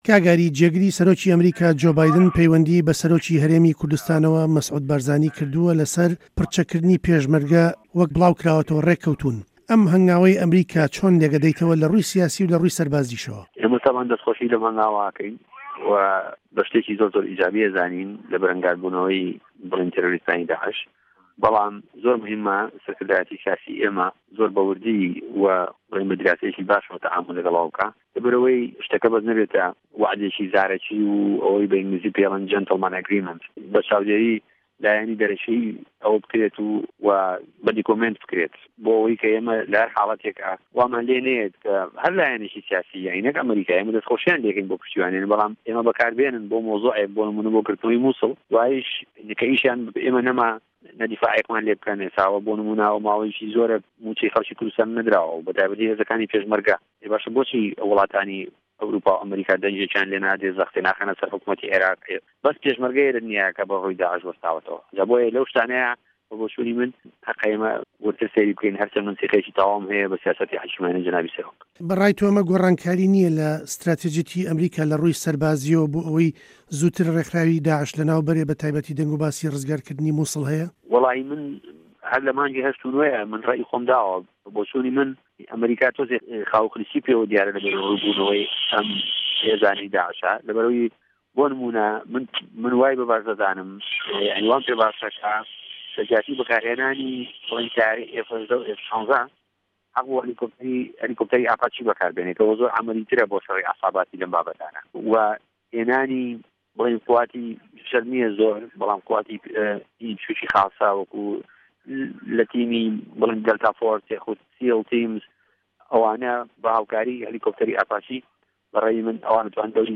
ئه‌مه‌ ته‌وه‌ری گفتووگۆی ده‌نگی ئه‌مریکایه‌ له‌گه‌ڵ ئاری هه‌رسین سه‌رۆکی لیژنه‌ی پێشمه‌رگه‌ له‌په‌رله‌مانی هه‌رێمی کوردستان.
گفتووگۆ له‌گه‌ڵ ئاری هه‌رسین